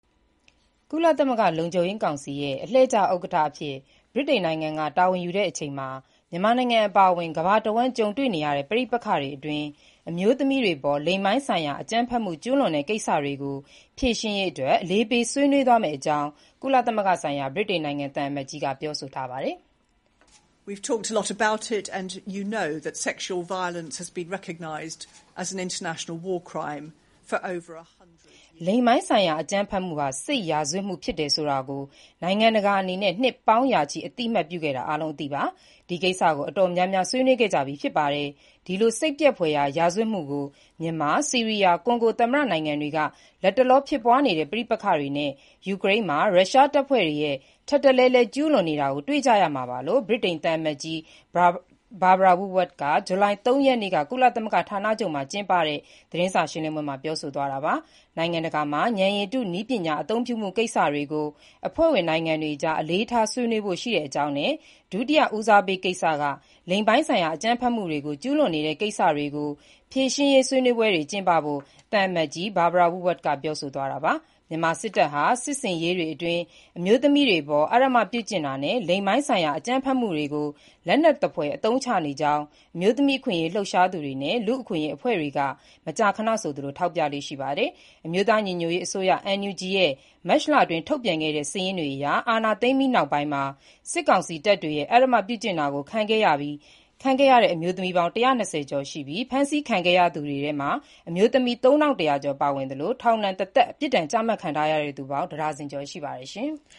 "လိင်ပိုင်းဆိုင်ရာ အကြမ်းဖက်မှုဟာ စစ်ရာဇဝတ်မှု ဖြစ်တယ်ဆိုတာကို နိုင်ငံတကာ အနေနဲ့ နှစ်ပေါင်းရာချီ အသတ်မှတ်ပြုခဲ့တာ အားလုံး အသိပါ။ ဒီကိစ္စကို အတော်များများ ဆွေးနွေးခဲ့ကြပြီး ဖြစ်ပါတယ်။ ဒီလို စိတ်ပျက်ဖွယ်ရာ ရာဇဝတ်မှုကို မြန်မာ၊ ဆီးရီးယား၊ ကွန်ဂို သမ္မတ နိုင်ငံတွေက လတ်တလော ဖြစ်ပွားနေတဲ့ ပဋိပက္ခတွေနဲ့ ယူကရိန်းမှာ ရုရှားတပ်ဖွဲ့တွေ ထပ်တလဲလဲ ကျုးလွန်နေကြတာ တွေ့ကြရမှာပါ"လို့ ဗြိတိန်သံအမတ်ကြီး Barbara Woodward က ဇူလိုင်လ ၃ ရက်နေ့က ကုလသမဂ္ဂ ဌာနချုပ်မှာကျင်းပတဲ့ သတင်းစာရှင်းလင်းပွဲမှာ ပြောဆိုသွားတာပါ။